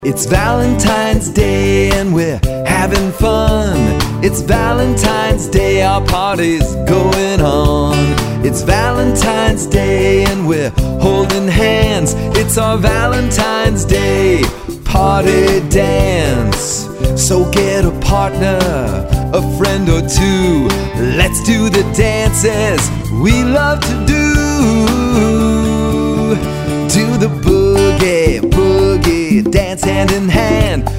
Valentine's Day song